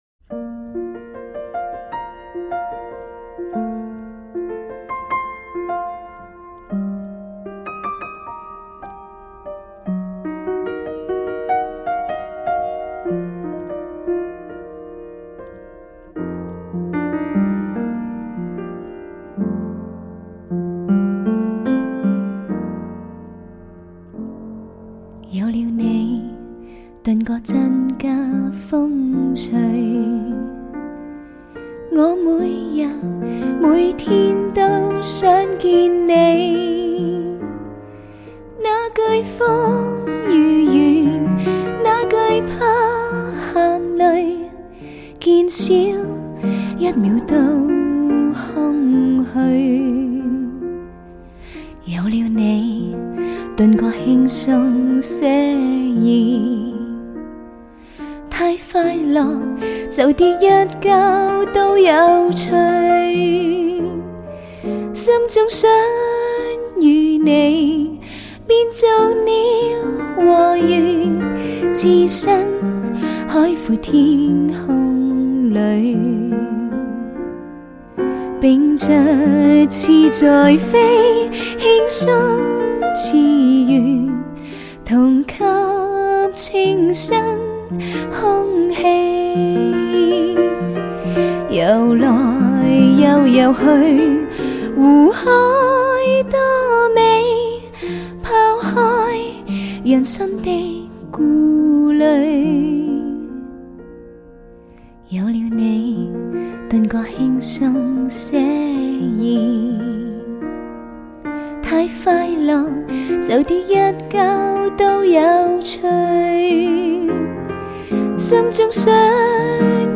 广东歌女声天碟之一
她青春和甜美的歌声得到不少发烧友的喜爱，
精确的乐器定位亦是一贯的无比吸引。